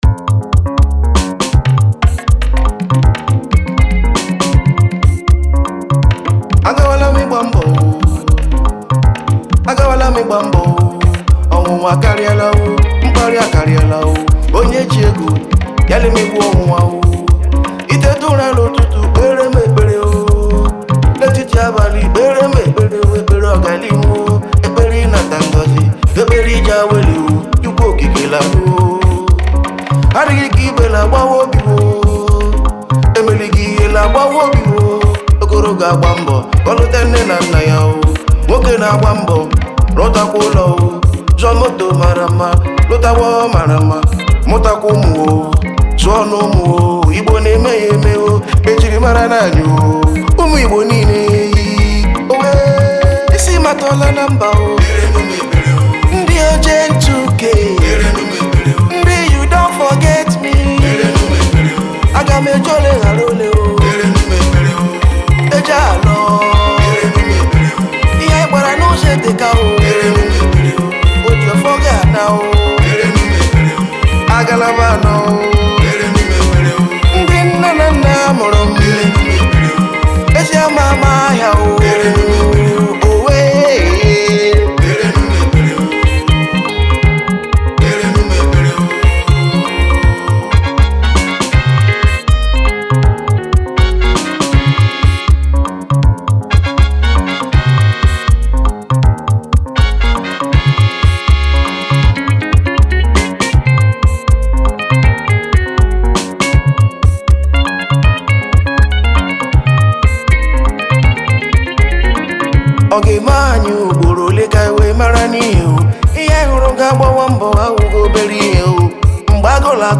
Home » Bongo » Highlife » Ogene